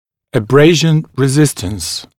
[ə’breɪʒn rɪ’zɪstəns][э’брэйжн ри’зистэнс]устойчивость к абразивному воздействию